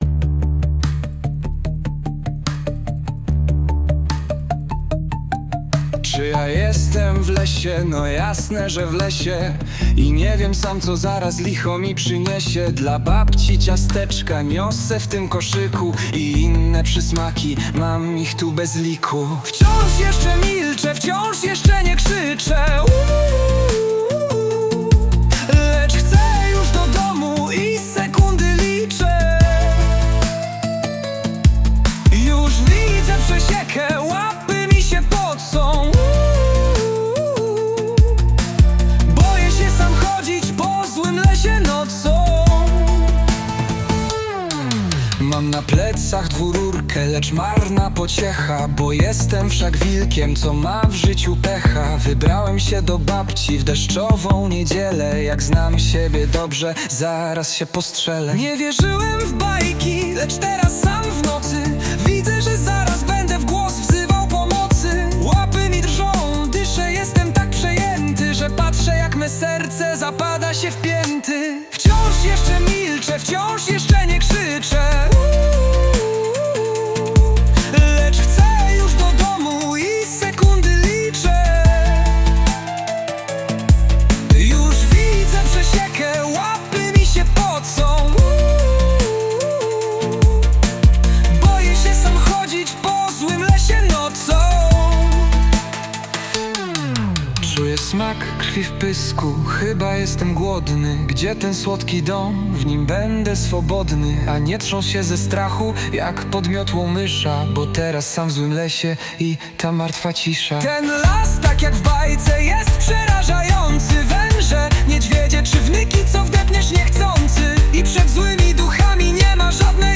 Powyższy utwór przerobiony przez AI na piosenkę.